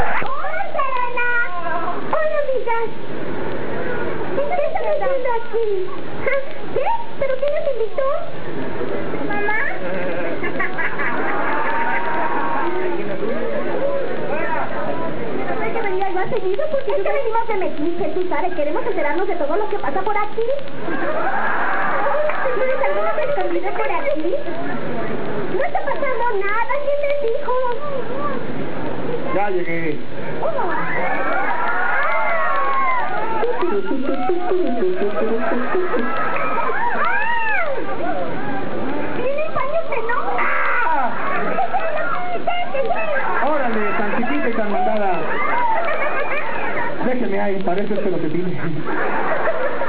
En la convención Roca Poca de marzo de 1997 se realizó una conferencia de doblaje que duró aproximadamente una hora.